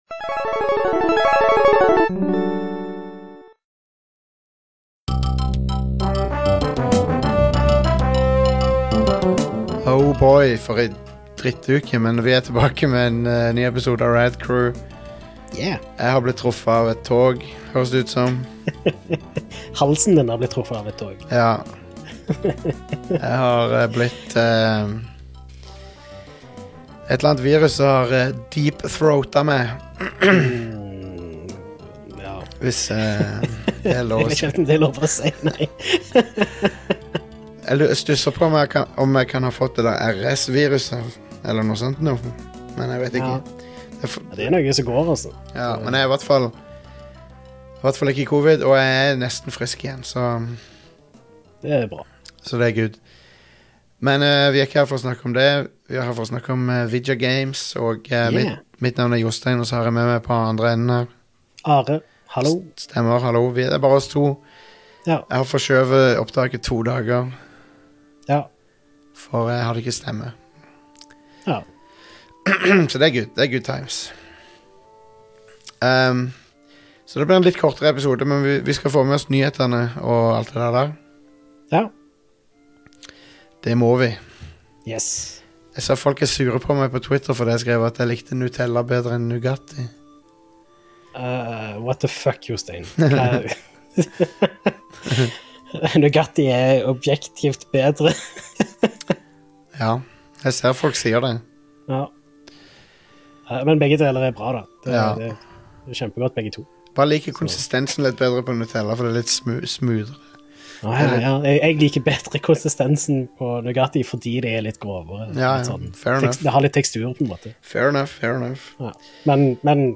Nyt denne litt kortere stemme med hes og fæl forkjølelsesstemme fra min side, så snakkes vi neste uke!